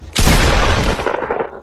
fortnite pump gun Meme Sound Effect
Category: Games Soundboard
fortnite pump gun.mp3